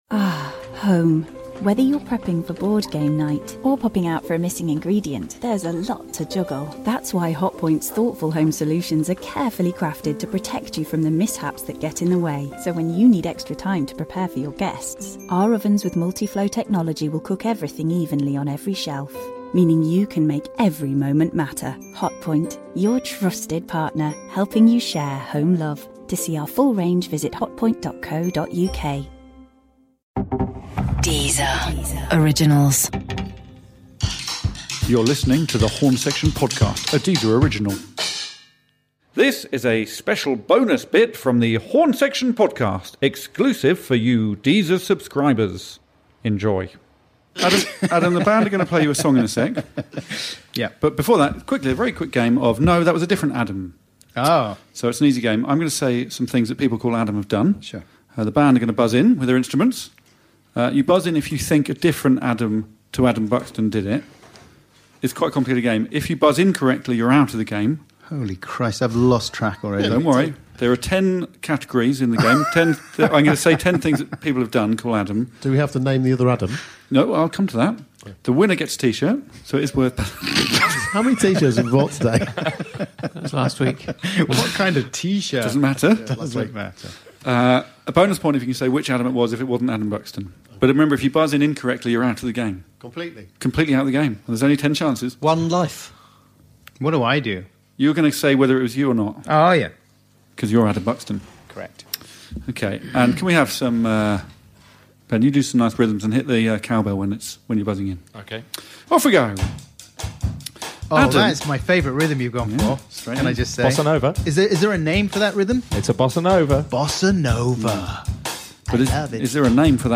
Raucous, ridiculous and surprisingly satisfying - it’s the Horne Section Podcast!